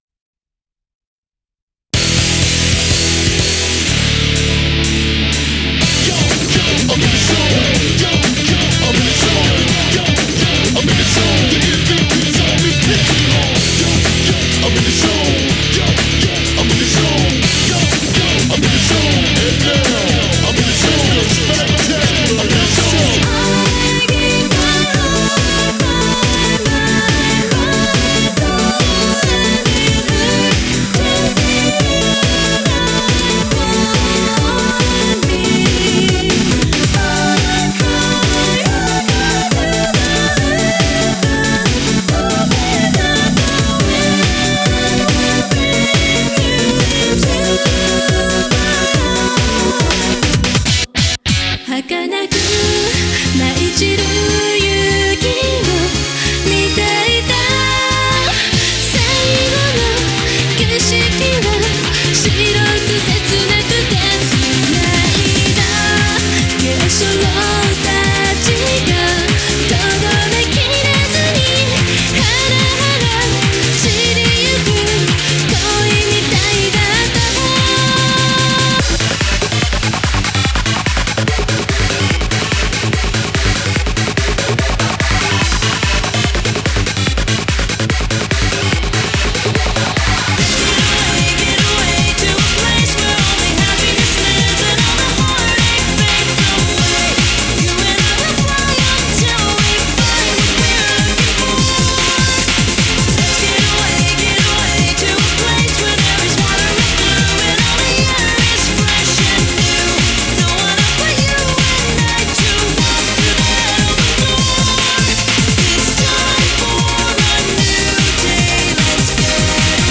BPM124-177